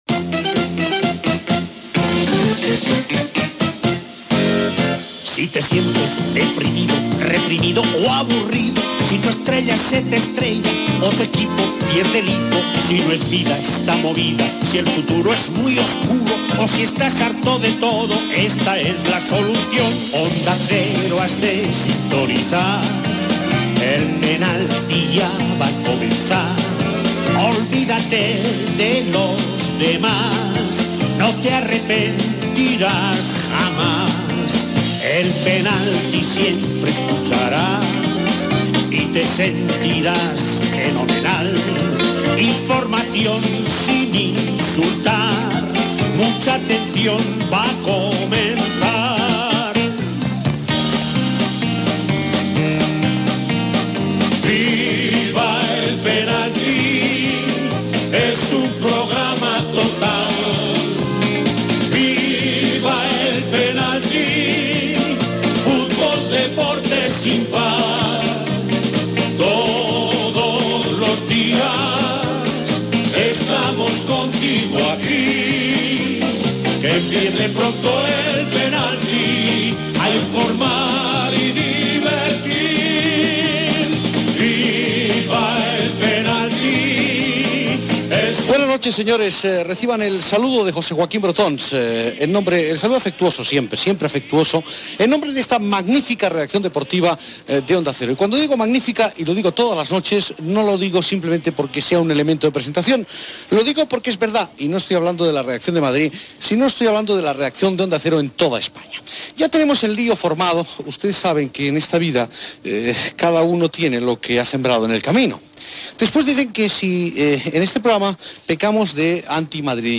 Sintonia cantada del programa, salutació inicial
Esportiu